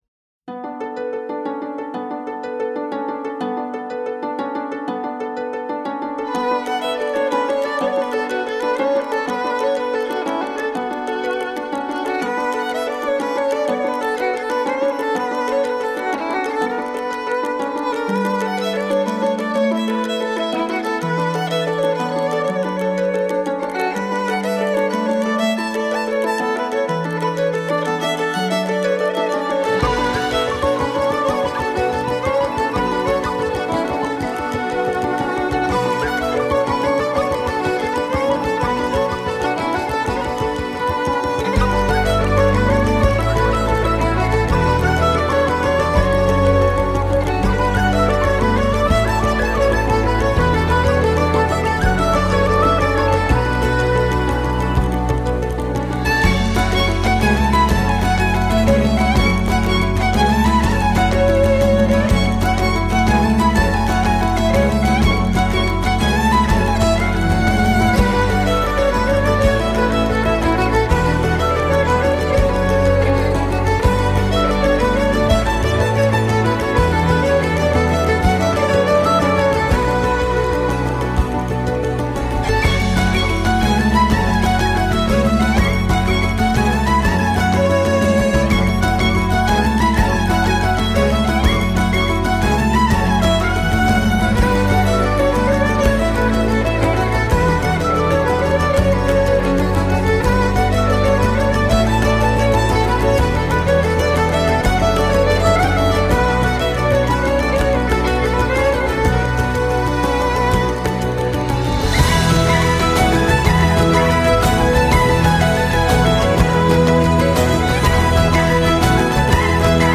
Кельтская_музыка_Средневековая_веселая_мелодия
Keljqtskaya_muzyka_Srednevekovaya_veselaya_melodiya.mp3